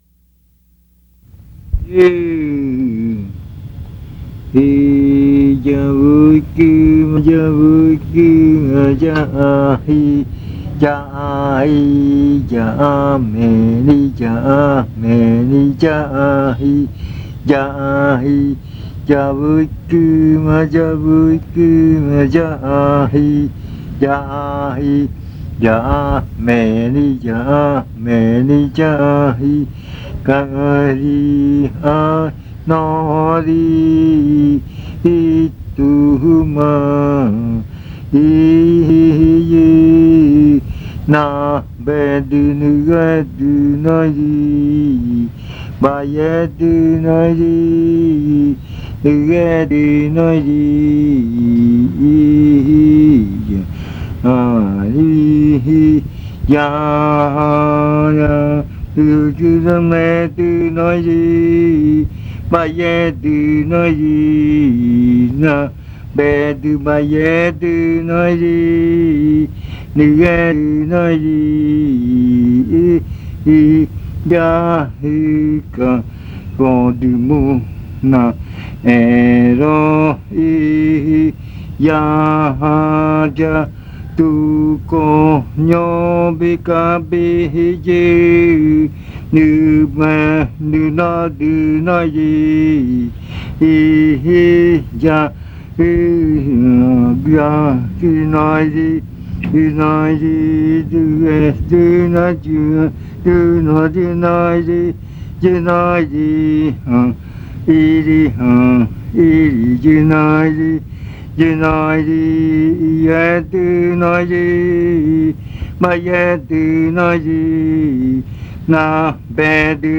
Leticia, Amazonas
Second part of the “Jagagɨ Jitoma” chant.